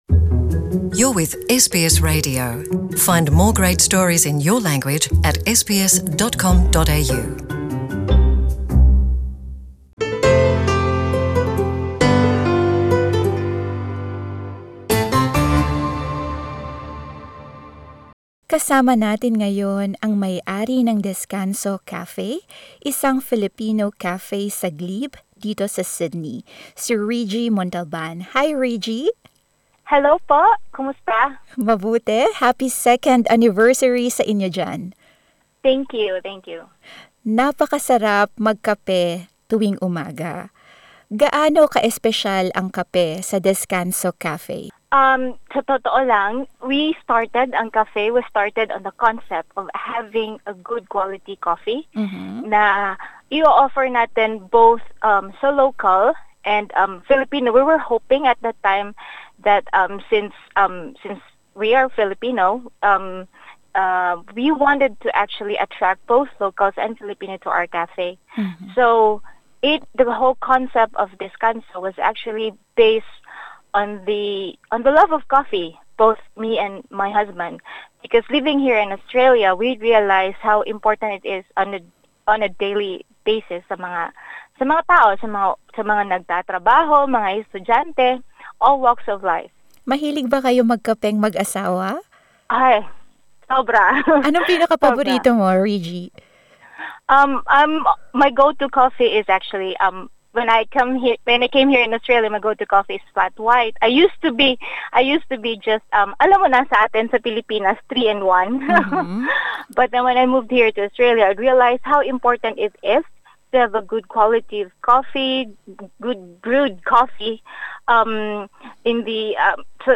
Tunog-Espanyol man ang pangalan nito, kilala ang cafe sa mga Pinoy na almusal. Narito ang aming panayam.